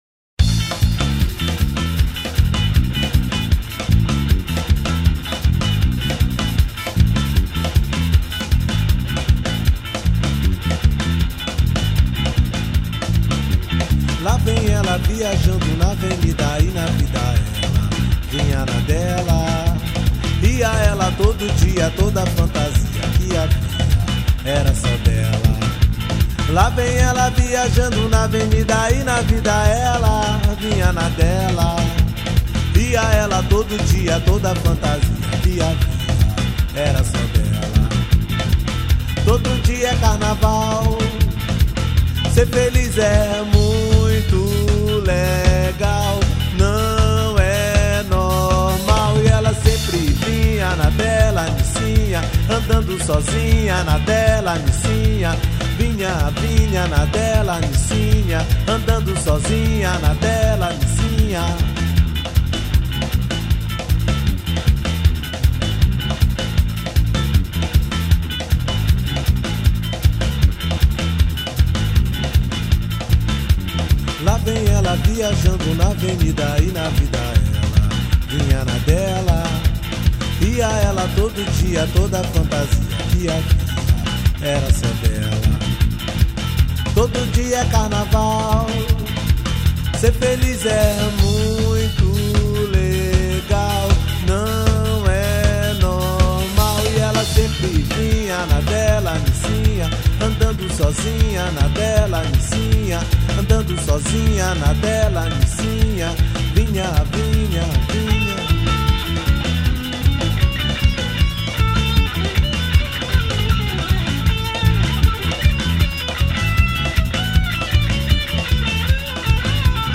2621   02:30:00   Faixa: 12    Reggae